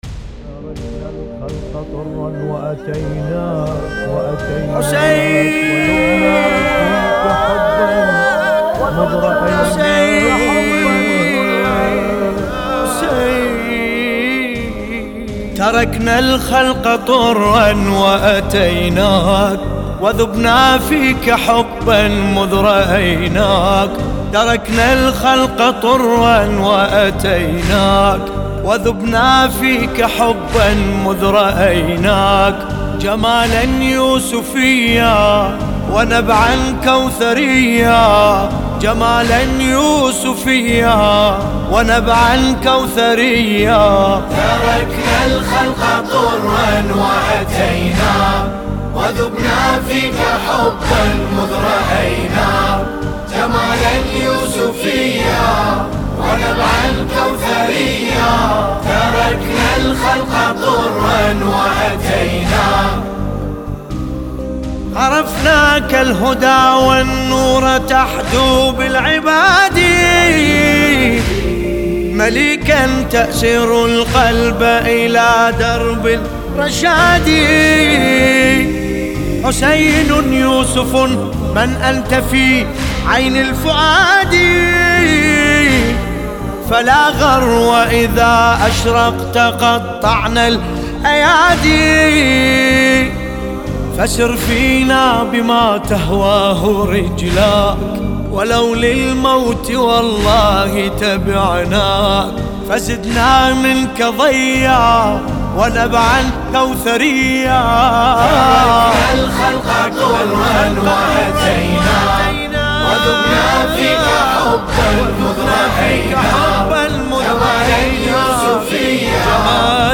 سينه زنی